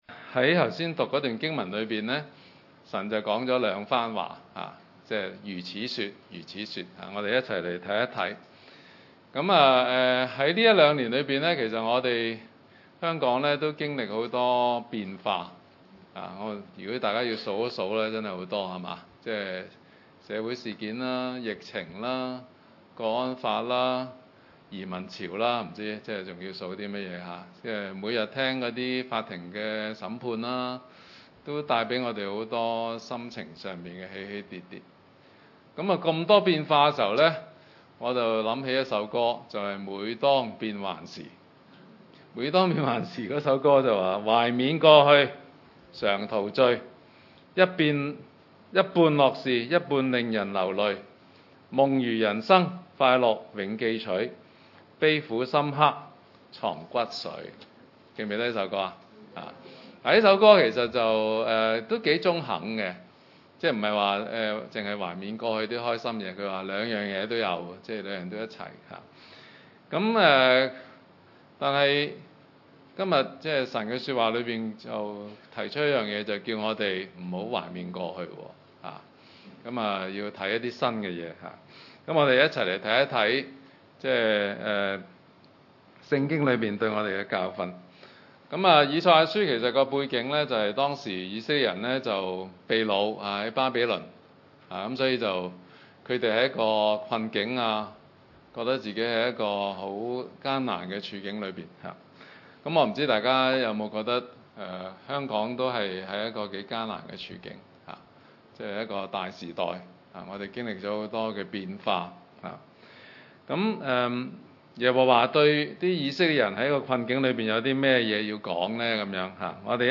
經文: 以賽亞書 43：14-21 崇拜類別: 主日午堂崇拜 耶和華─你們的救贖主、以色列的聖者如此說：因你們的緣故，我已經打發人到巴比倫去；並且我要使迦勒底人如逃民，都坐自己喜樂的船下來。